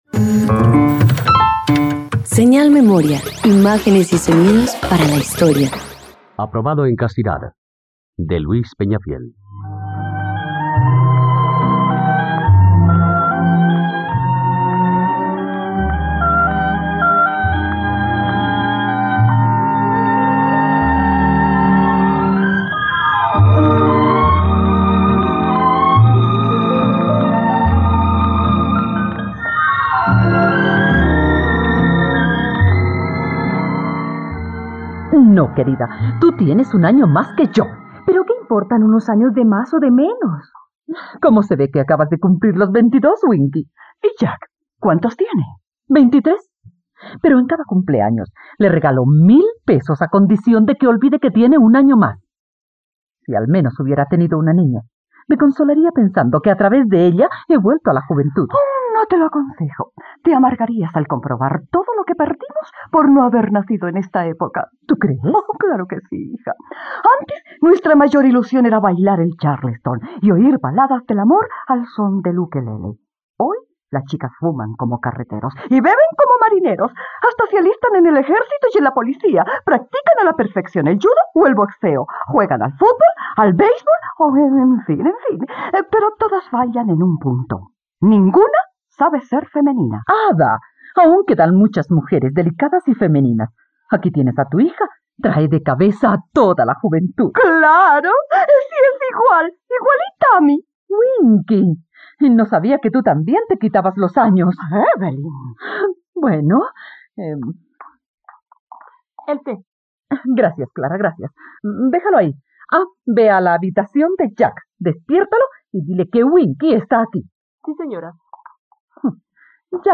..Radioteatro. Escucha la adaptación radiofónica de “Aprobado en castidad” de Luis Peñafiel por la plataforma streaming RTVCPlay.